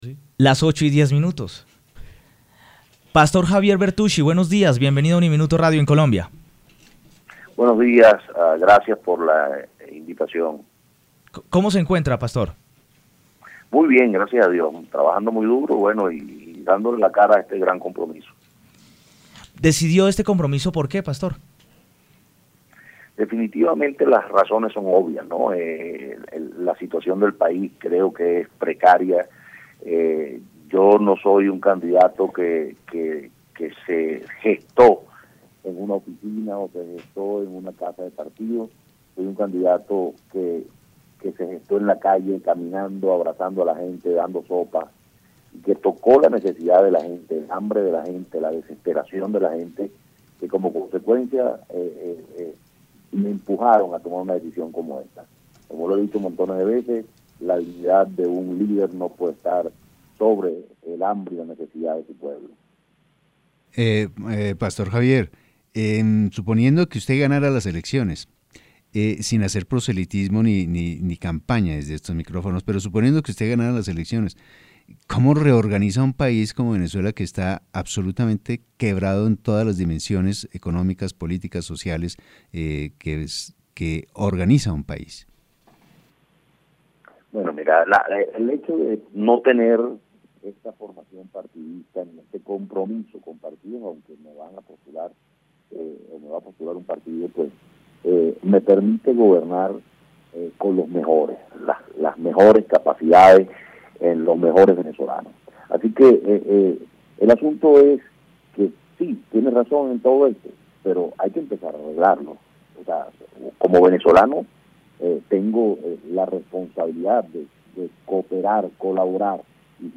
En diálogo exclusivo con el programa Aquí y Ahora en UNIMINUTO Radio, el guía espiritual venezolano indicó que vencerá las probabilidades en su contra para convertirse en el máximo mandatario del país vecino.